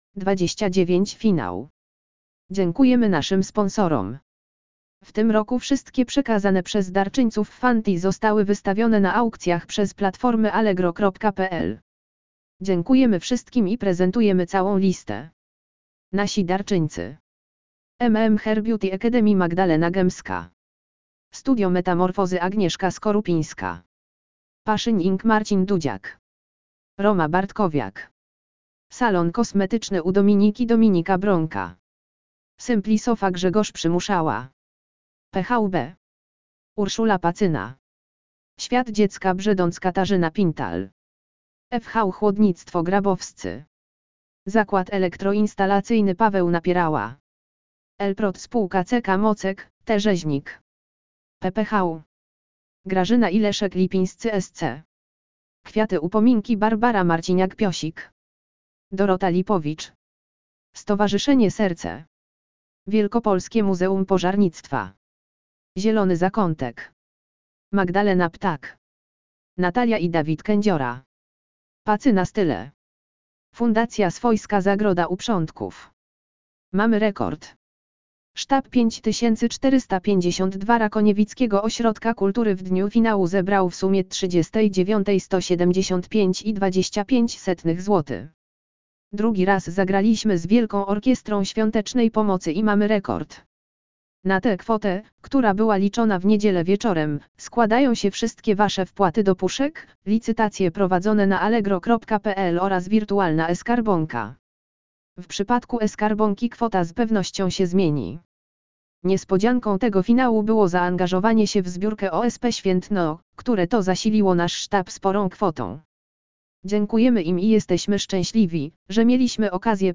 Lektor audio opis 29 FINAŁ WOŚP
lektor_audio_opis_29_final_wosp.mp3